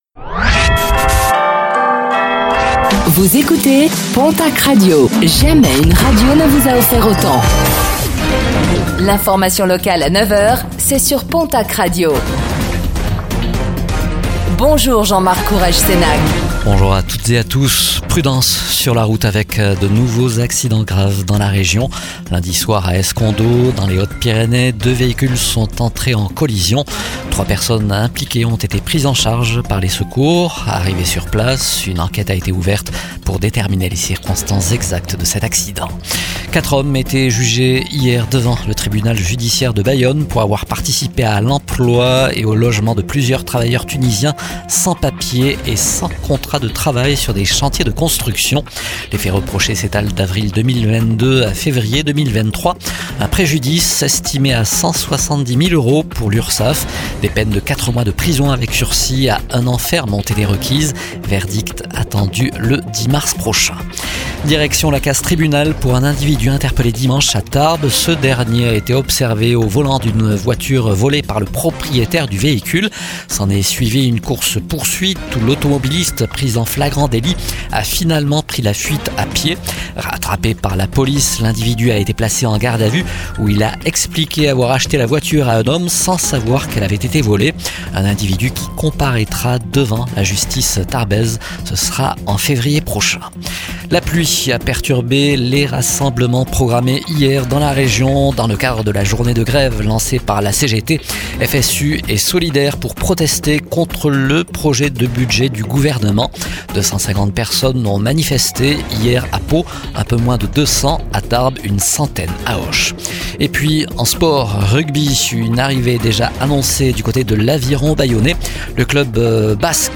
Infos | Mercredi 03 décembre 2025